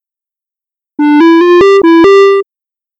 arcade victory videogame sound effect free sound royalty free Gaming